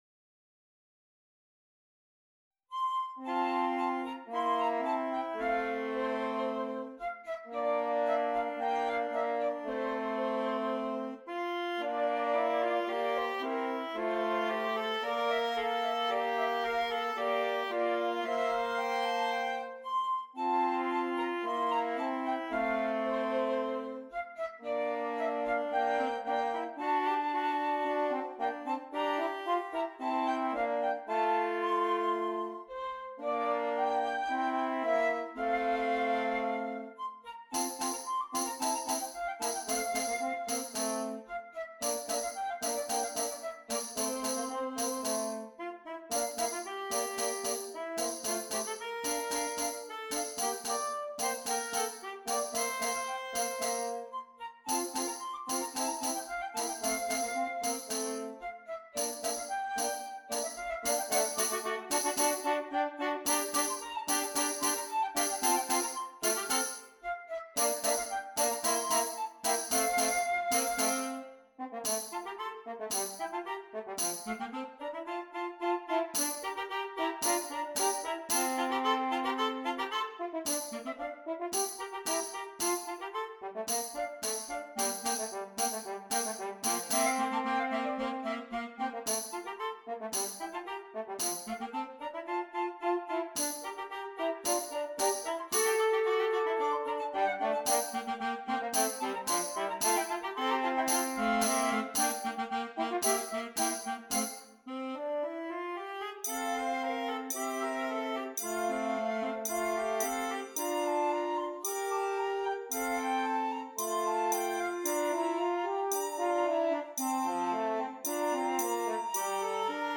Interchangeable Trio with Optional Percussion